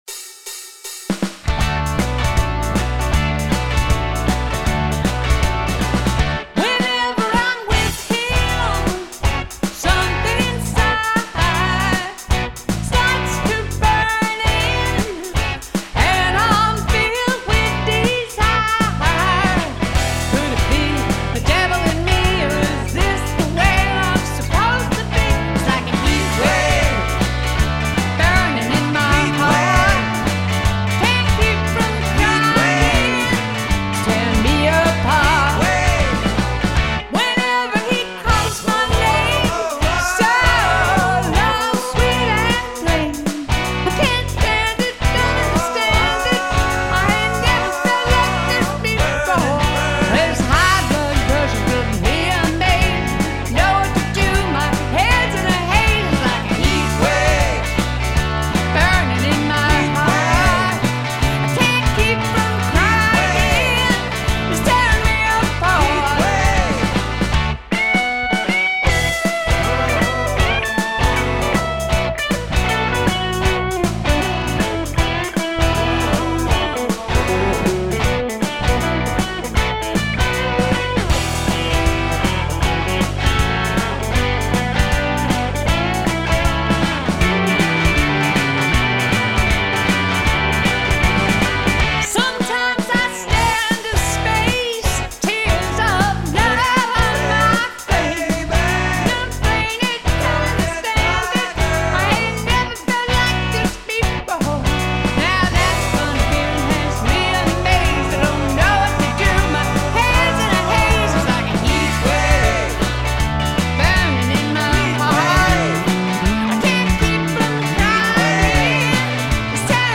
The Recording Studio